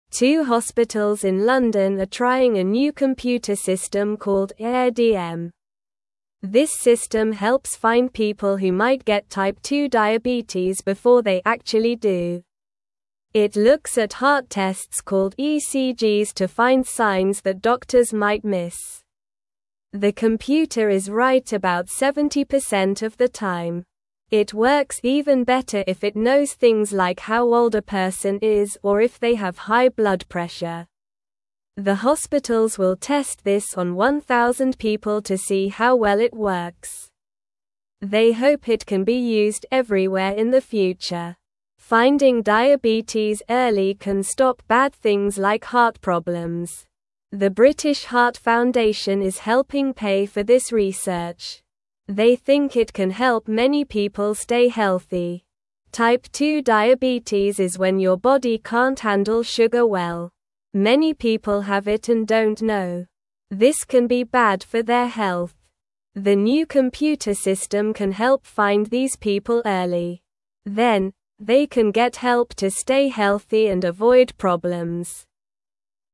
Slow
English-Newsroom-Beginner-SLOW-Reading-New-Computer-Helps-Find-Diabetes-Early-in-People.mp3